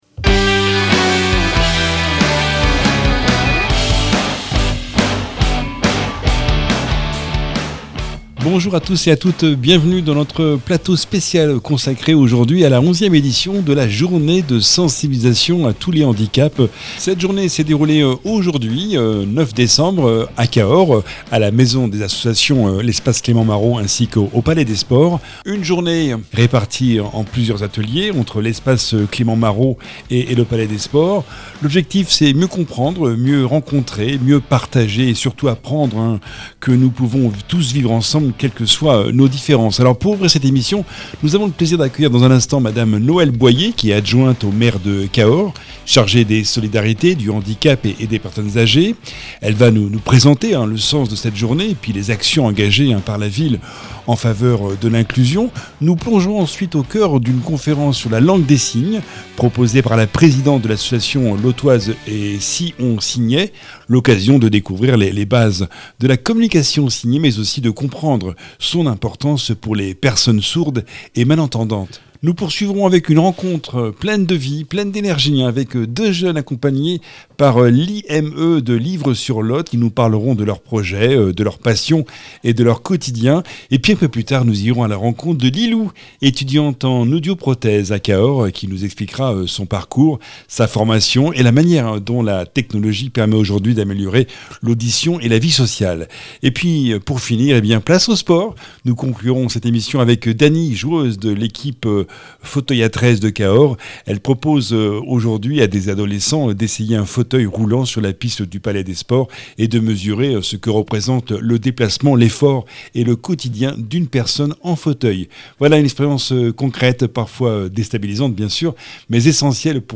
Podcast spécial “Vivre Ensemble” – 11e journée de sensibilisation à tous les handicaps enregistré le 9 décembre à l’espace Clément Marot et le palais des Sports de Cahors.